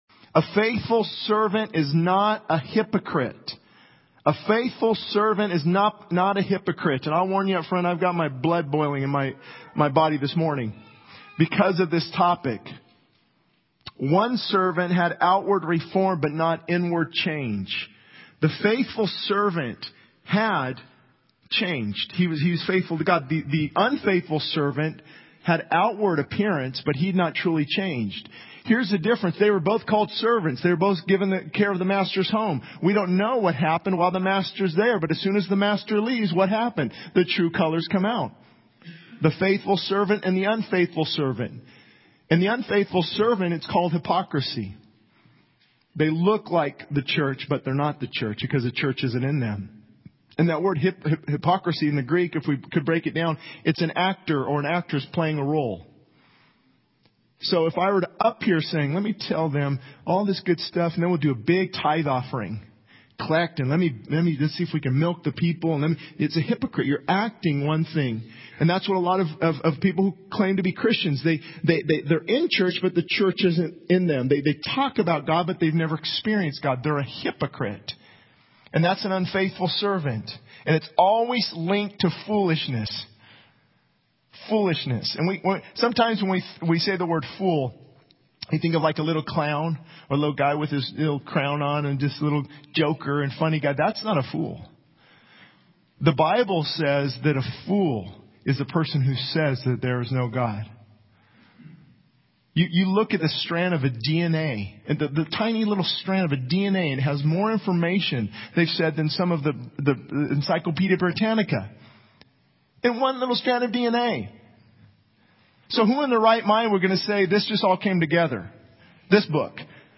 This sermon emphasizes the importance of being a faithful servant who is not a hypocrite. It contrasts the outward appearance of faith with true inward change, highlighting the need for a genuine relationship with God.